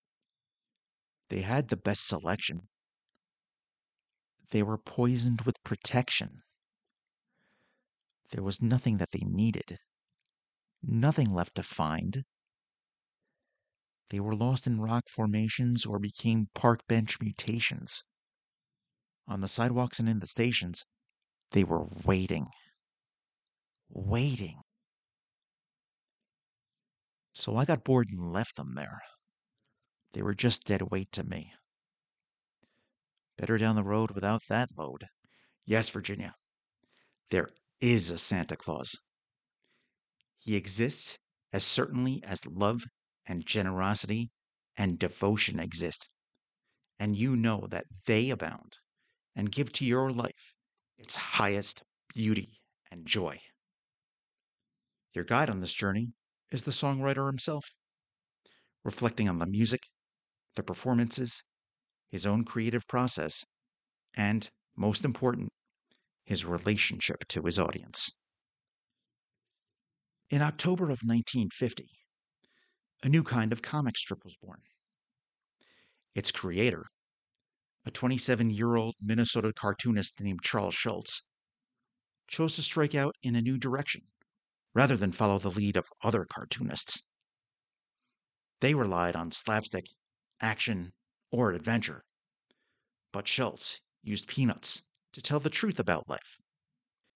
English - USA and Canada
Accents and Dialects
New York
Middle Aged